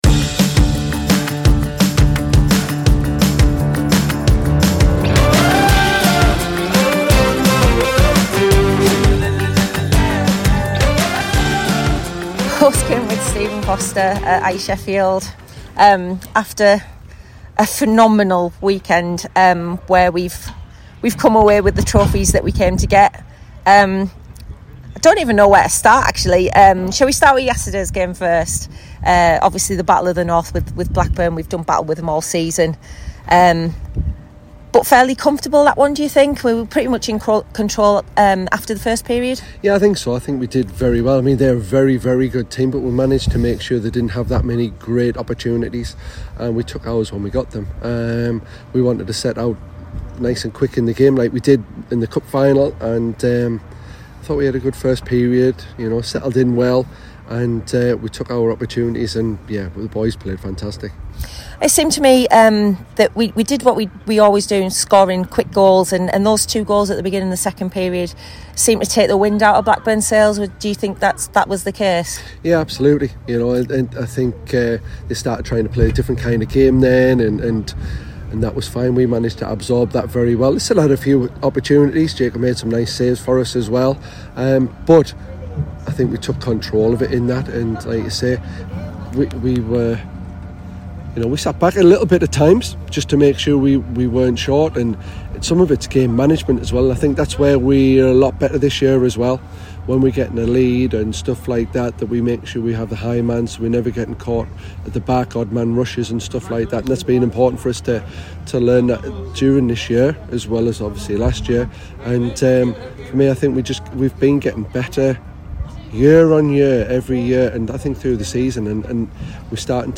Hear the smiles in the voices